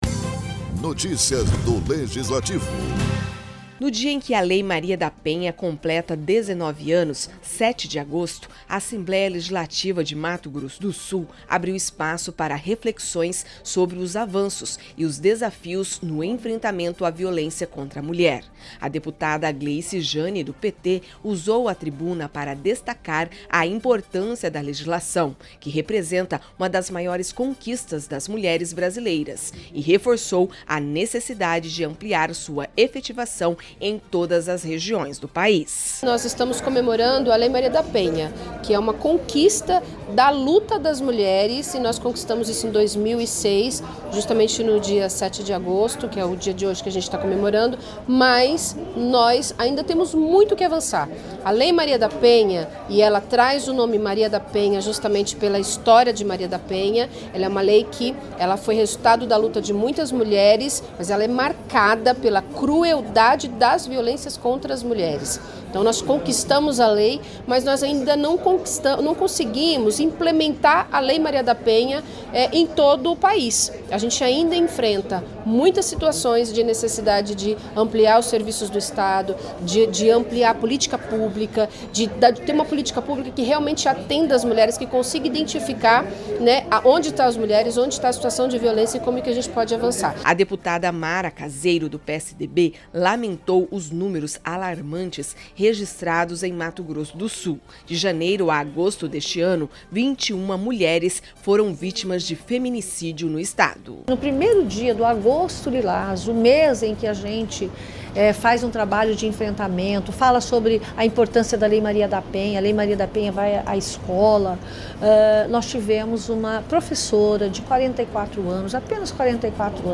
A deputada Gleice Jane, do PT, usou a tribuna para destacar a conquista da legislação e reforçou a necessidade de ampliar sua efetivação em todas as regiões do país.